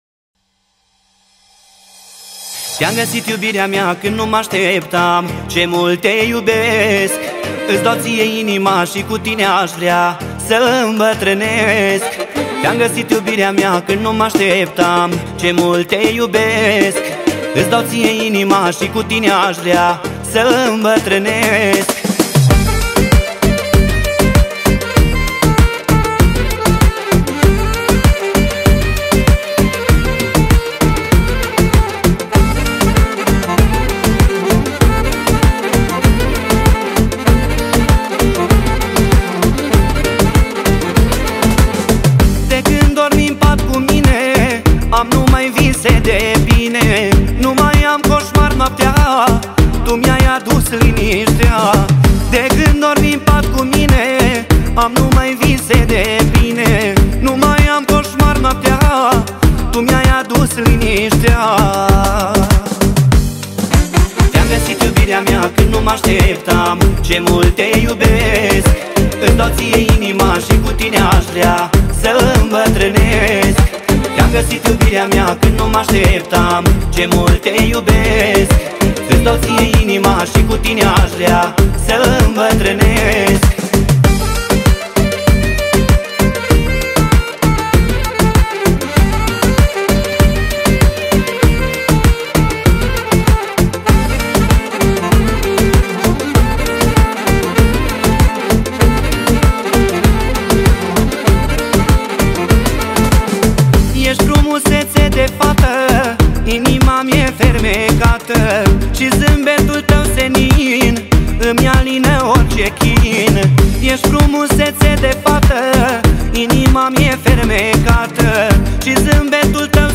Data: 06.10.2024  Manele New-Live Hits: 0